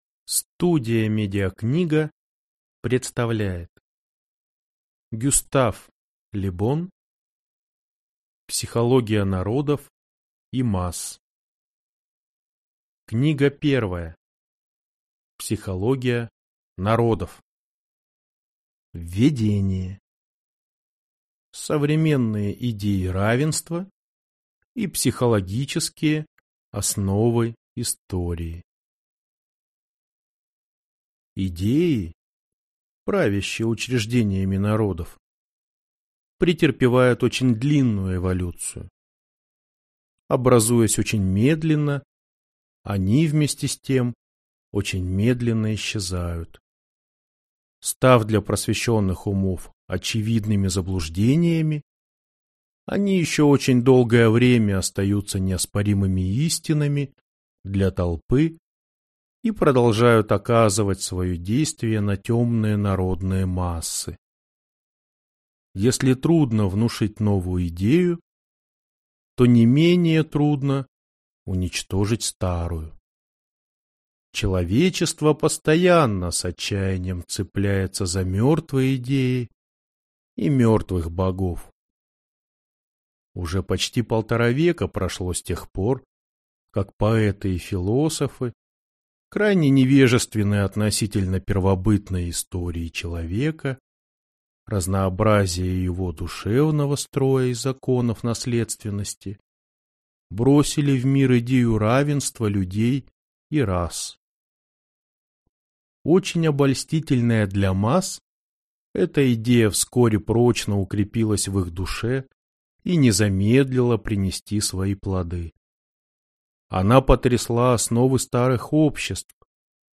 Аудиокнига Психология народов | Библиотека аудиокниг